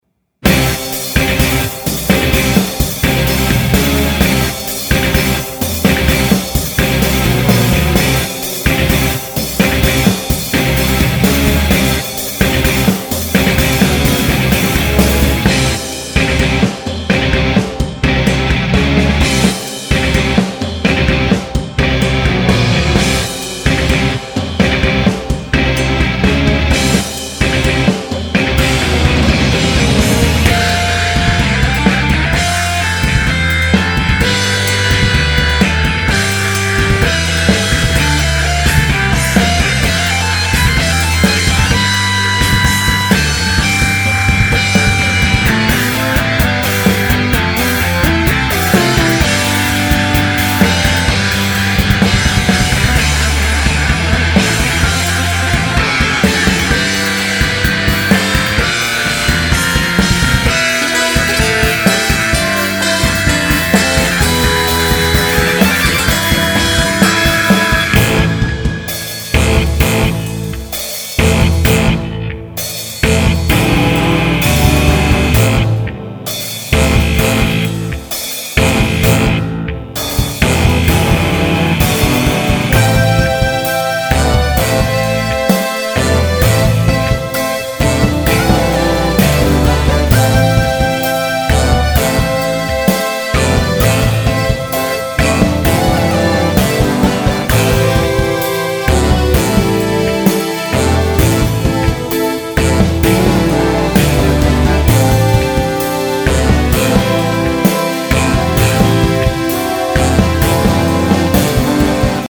ゲイン上げたので音量やや注意。
stereo outで高域を持ち上げたほうが良さそうだけど一回通しで直してからにしよう（メモ）